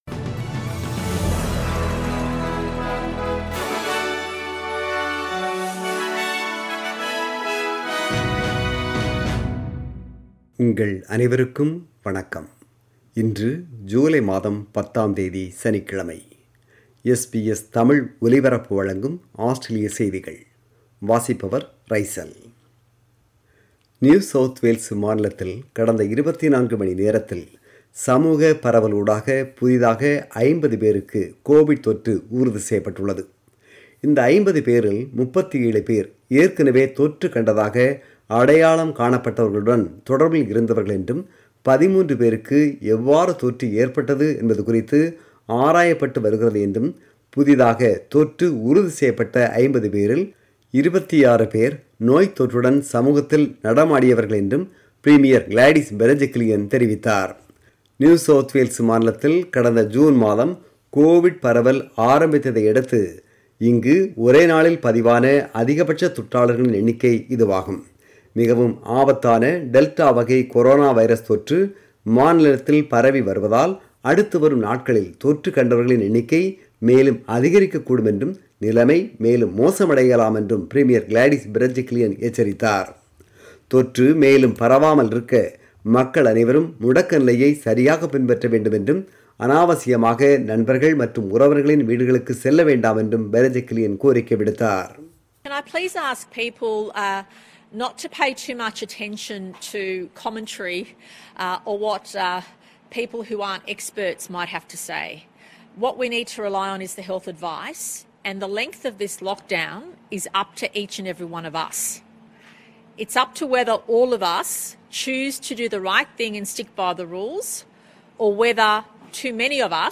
ஆஸ்திரேலிய செய்திகள்